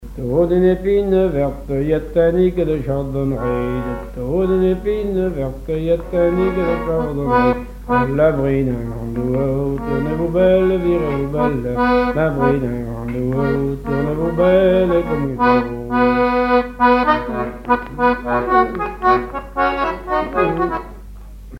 Divertissements d'adultes - Couplets à danser
branle : courante, maraîchine
Répertoire sur accordéon diatonique
Pièce musicale inédite